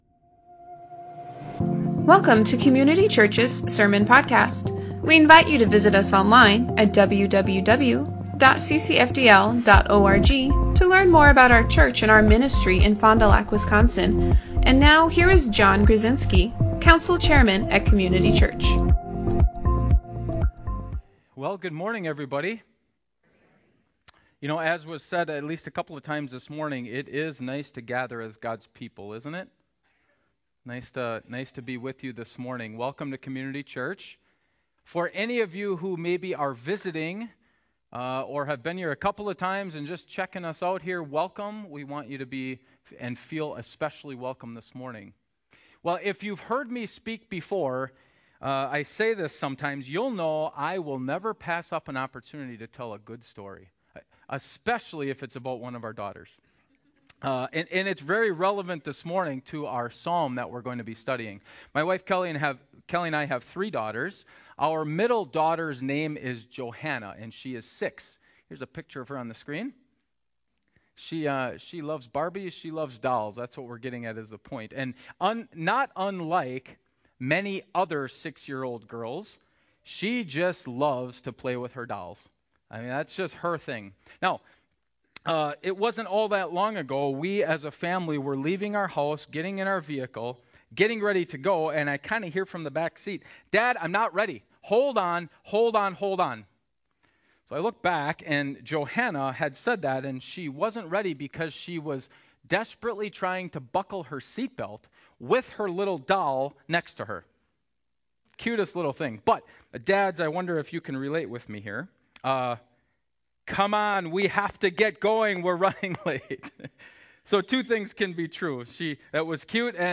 Sermons | Community Church Fond du Lac